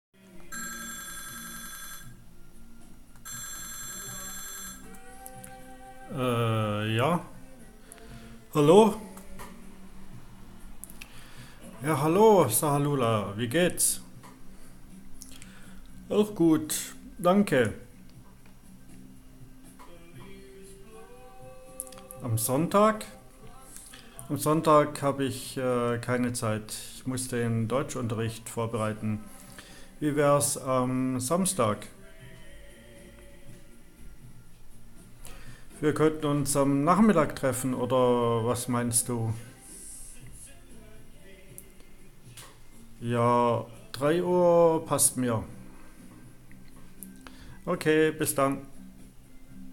Ergänzen Sie den Dialog